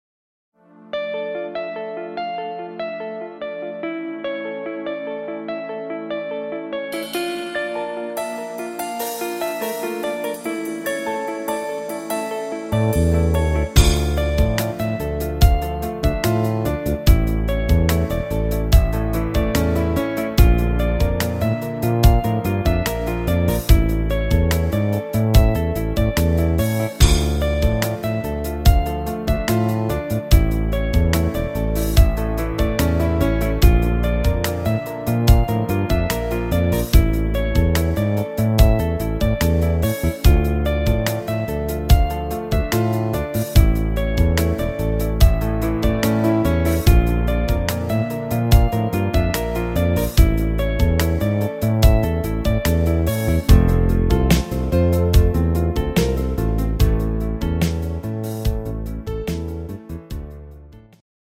instrumental Akkordeon